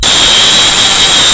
Five Nights At Labubu Jumpscare Sound